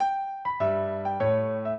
minuet10-2.wav